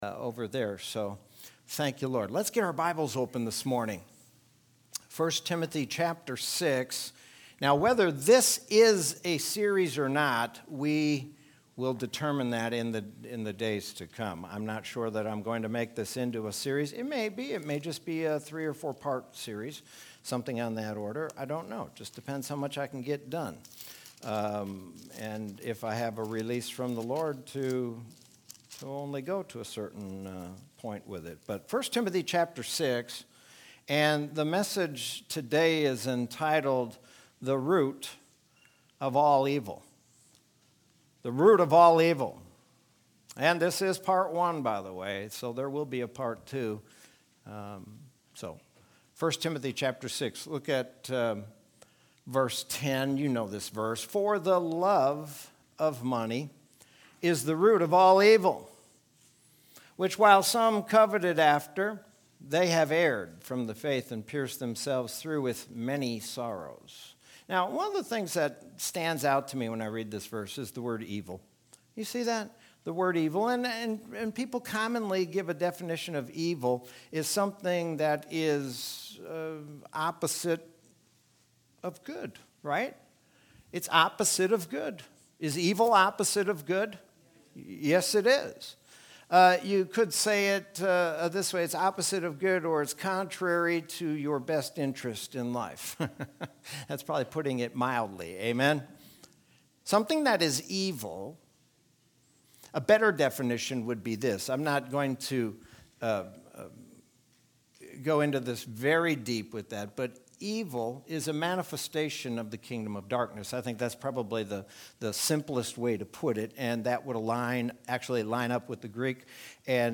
Sermon from Sunday, January 24th, 2021.